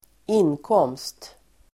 Uttal: [²'in:kåm:st]